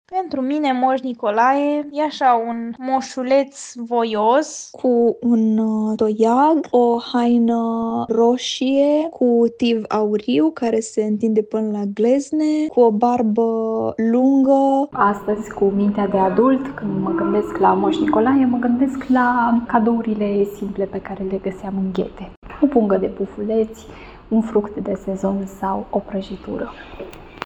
„Pentru mine Moș Nicolae e un moșuleț voios, cu un toiag, o haină roșie, cu tiv auriu care se întinde până la glezne, o barbă lungă”, îl descrie o femeie.
„Astăzi, cu mintea de adult, când mă gândesc la Moș Nicolae, mă gândesc la cadourile simple pe care le găseam în ghete: o pungă de pufuleți, un fruct de sezon sau o prăjitură”, spune o altă femeie.